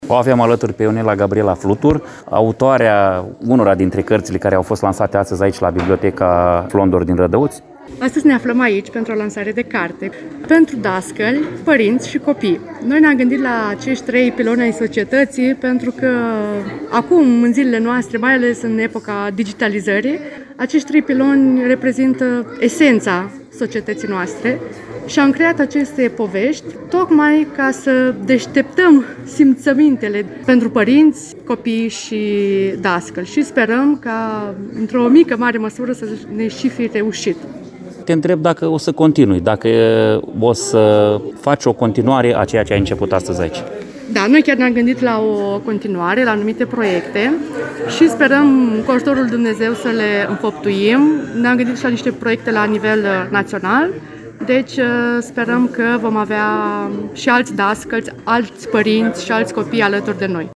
Pe 30 noiembrie, la Biblioteca Tudor Flondor din Rădăuți au avut loc o serie de lansări de carte.